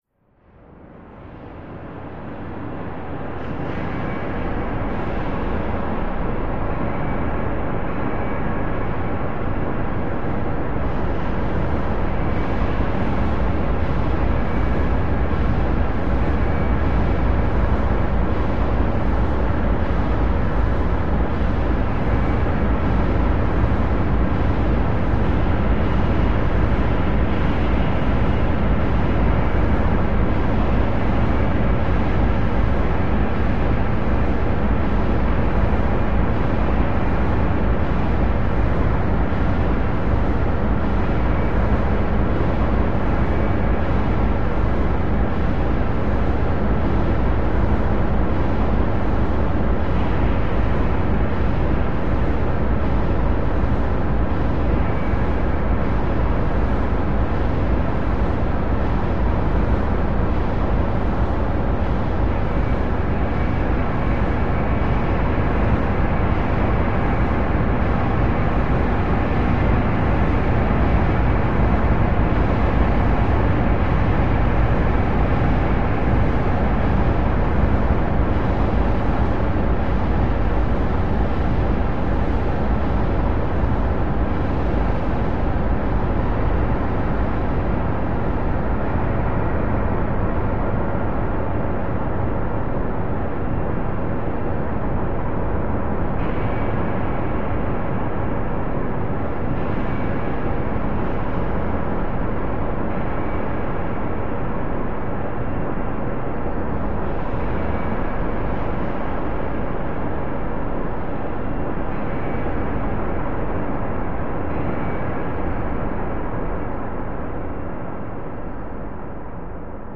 Airy Distant Machine Room Drone Machine, Room, Airy, Distant